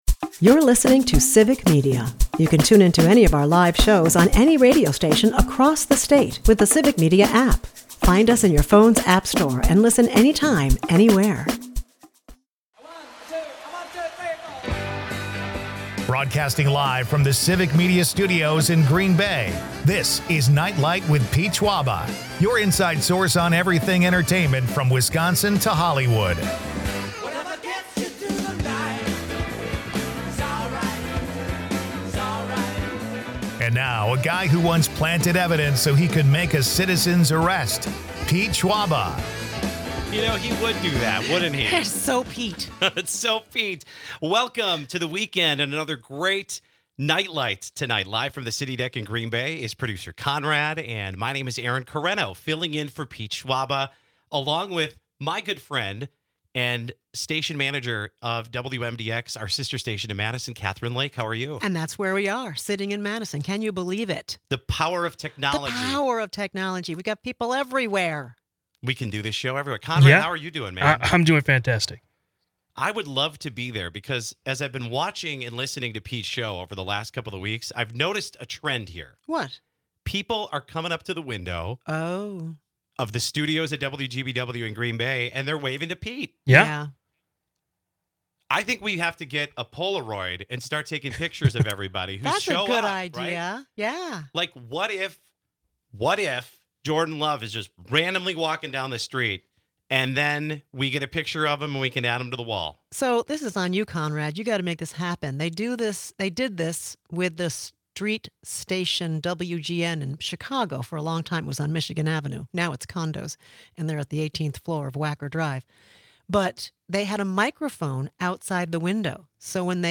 Broadcasting from Civic Media Studios in Madison